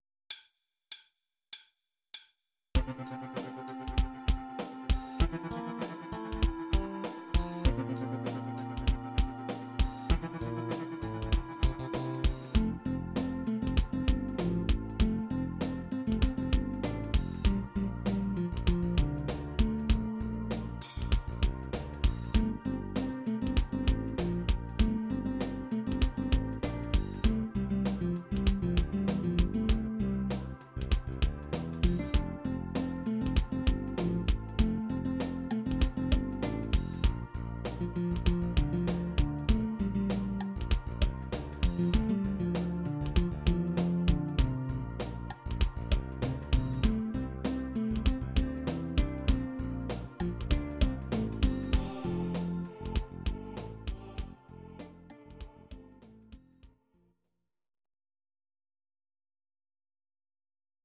Audio Recordings based on Midi-files
Pop, Rock, 1990s